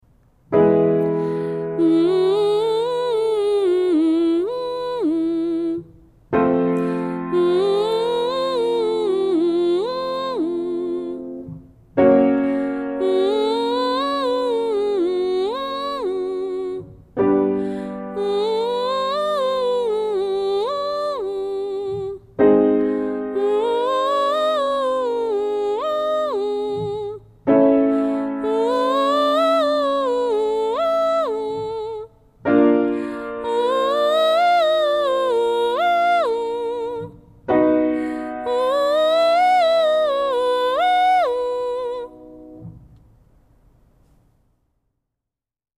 Exercices réchauffement humming mi maj